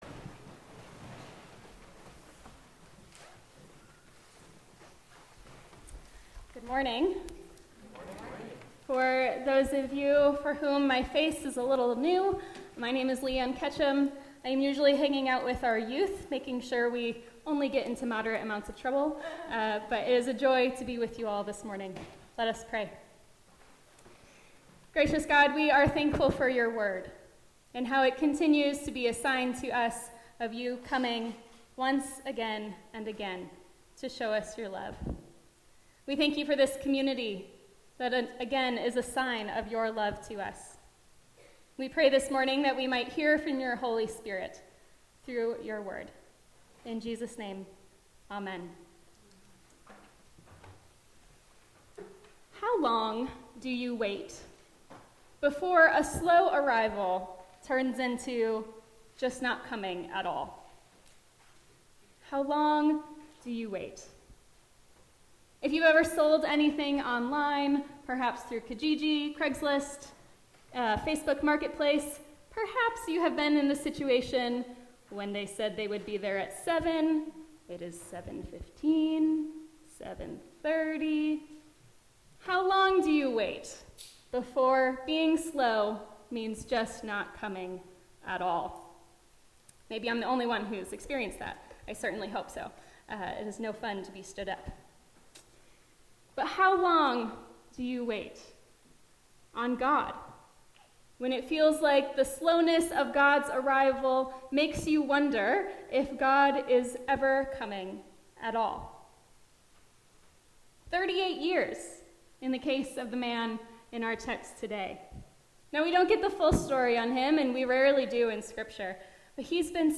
Sermons | Kingsway-Lambton United Church